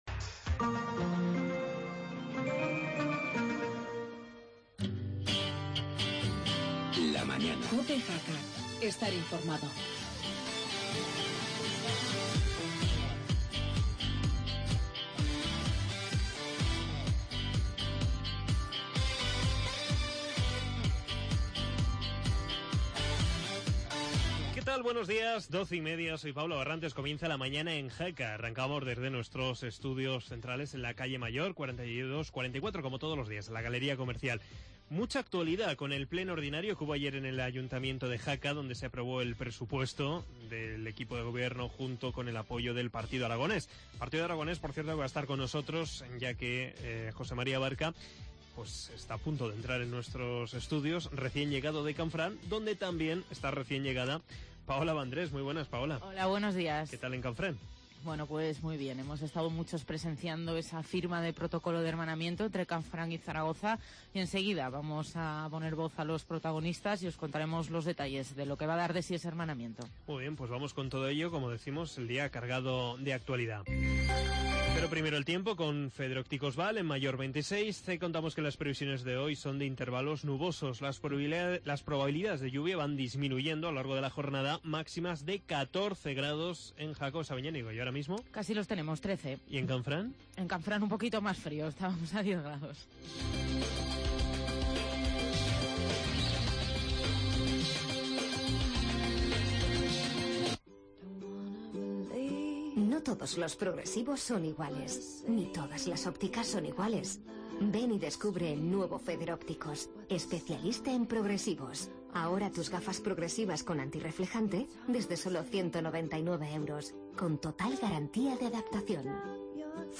AUDIO: Con la actualidad, resumen del pleno de ayer en el Ayto de Jaca, hermanamiento Canfranc-Zaragoza y entrevista al presidente de la Comarca...